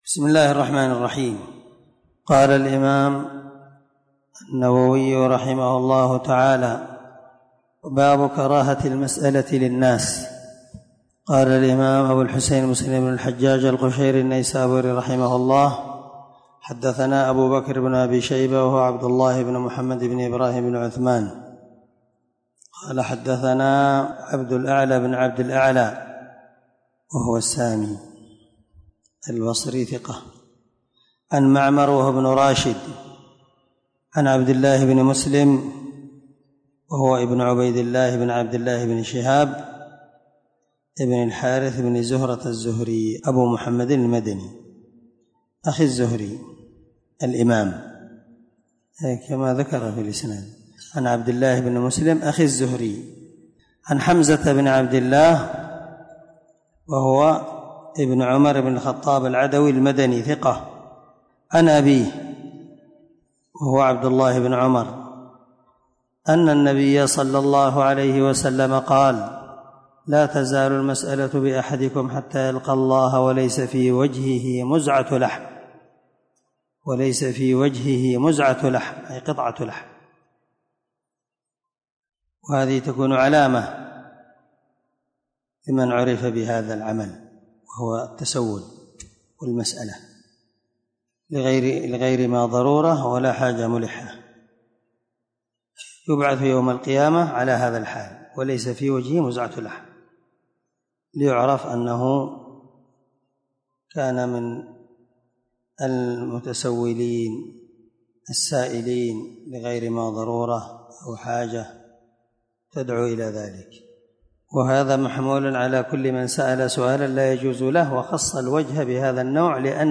639الدرس 47من شرح كتاب الزكاة حديث رقم(1040_1041) من صحيح مسلم